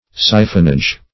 Siphonage \Si"phon*age\, n. The action of a siphon.